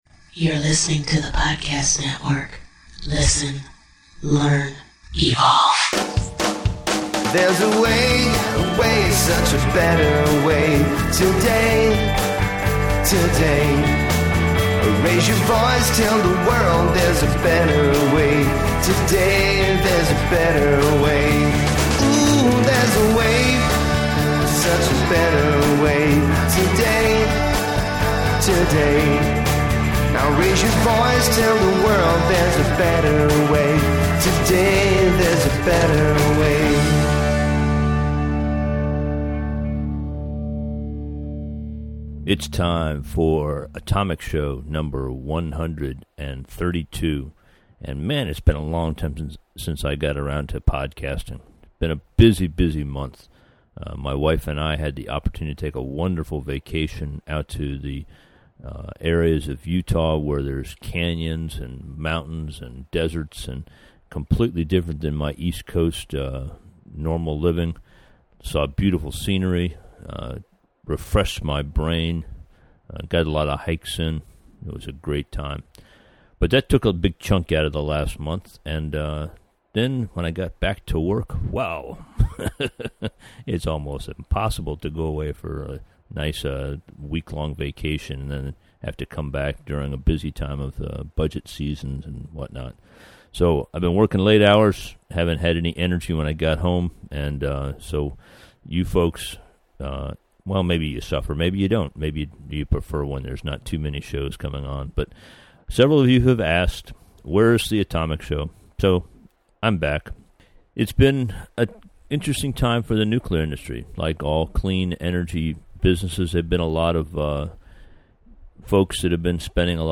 He asked a lot of good, probing questions with an open attitude.